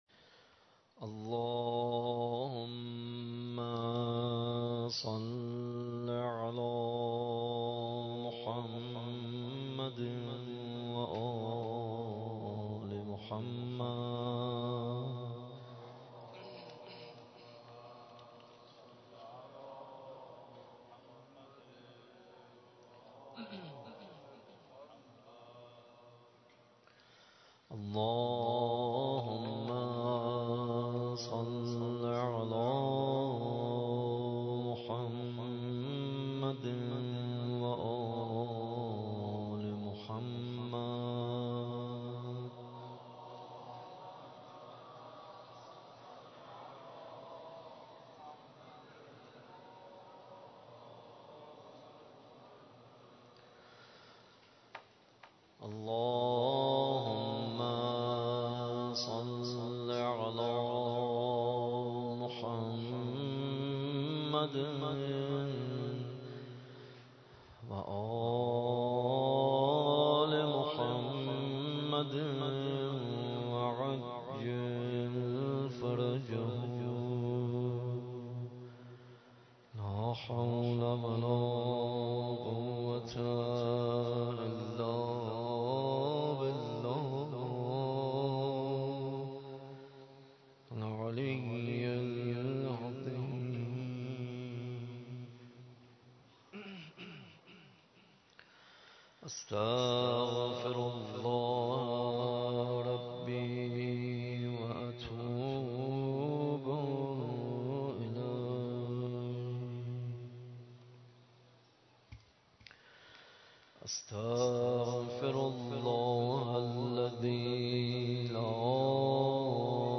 مناجات خوانی
در شب نهم ماه رمضان 93